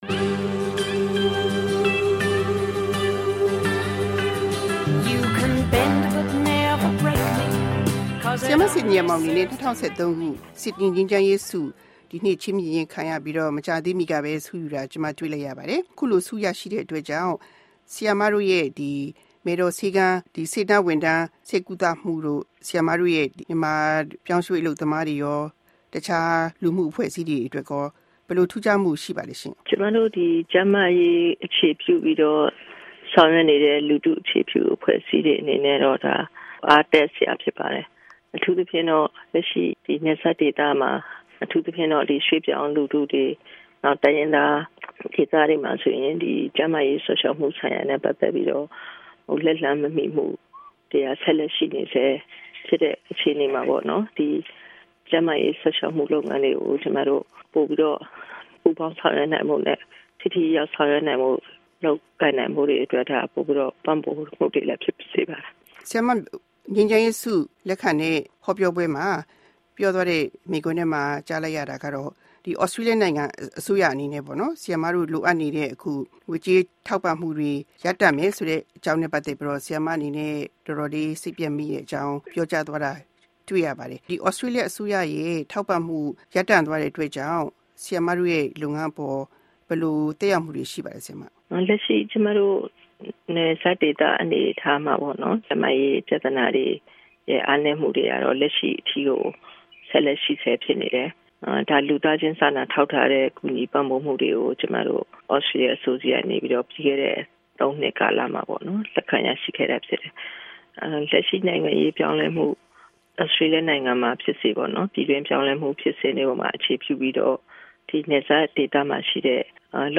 အမျိုးသမီးကဏ္ဍ - ဒေါက်တာစင်သီမောင်နဲ့မေးမြန်းခန်း